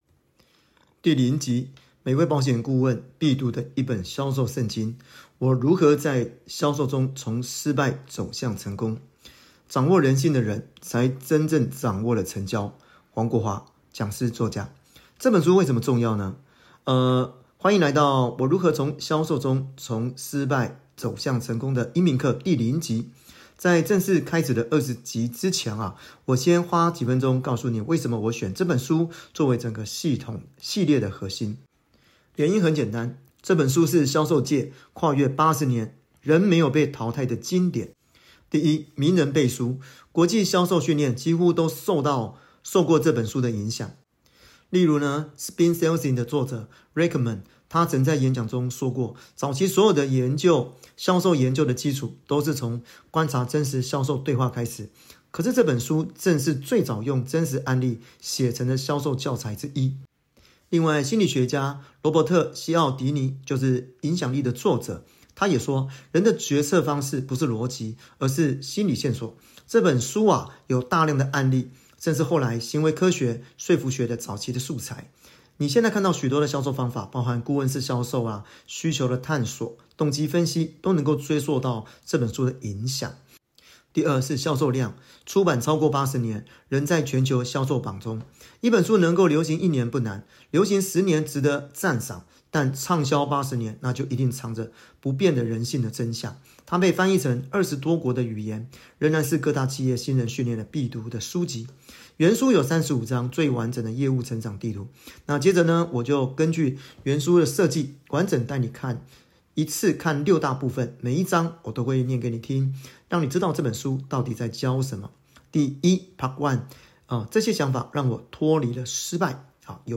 歡迎來到《我如何在銷售中從失敗走向成功》音頻課的第零集。
接下來，我會根據「原書設計」完整帶你看一次六大部分，每一章我都會念給你聽，讓你知道這本書到底在教什麼。